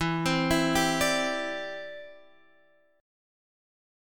Em7#5 Chord